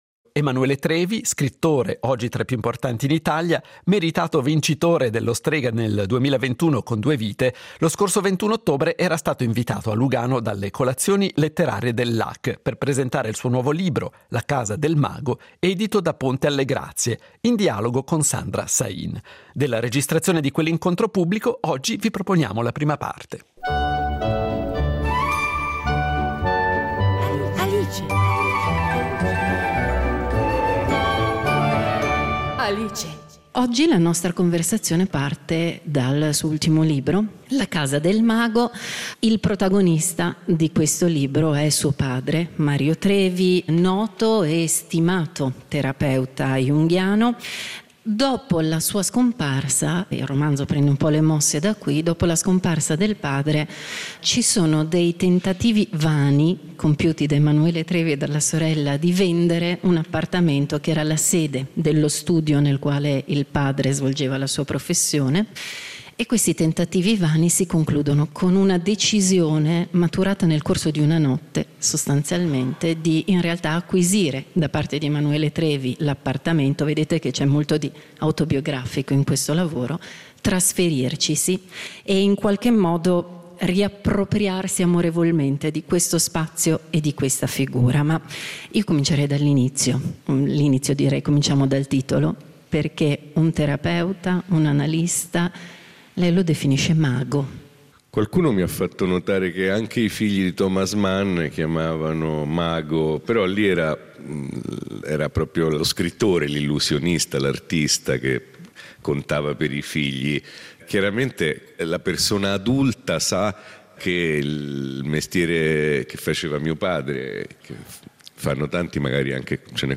Intervista a Emanuele Trevi